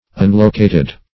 Unlocated \Un*lo"ca*ted\, a.